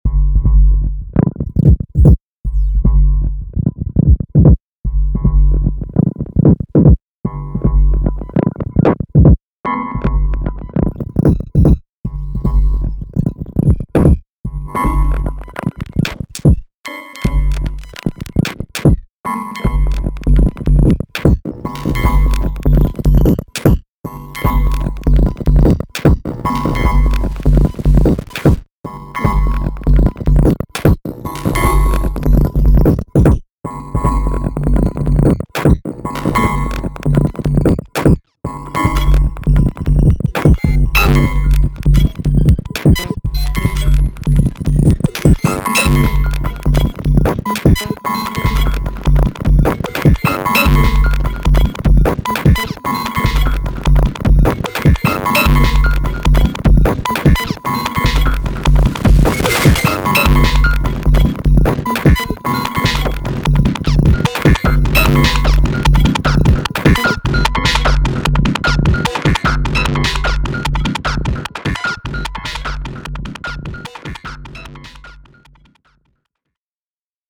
It inspired me to make a wall of machine sound, too.